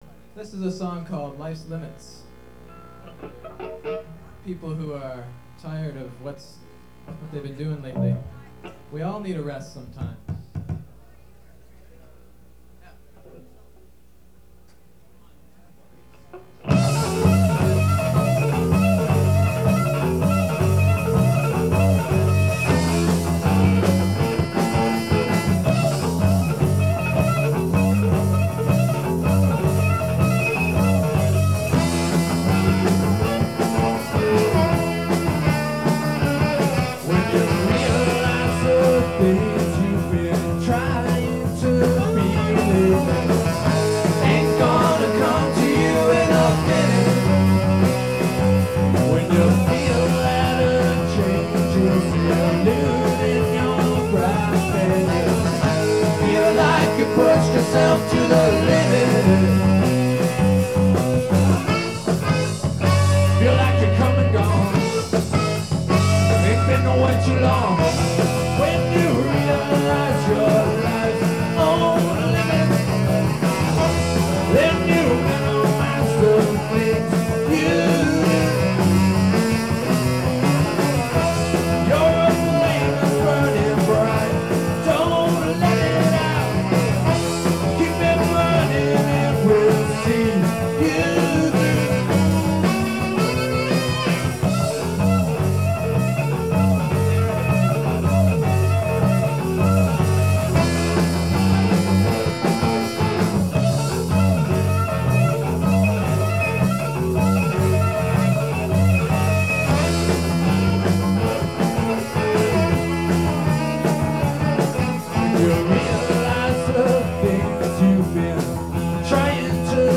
guitar, vocals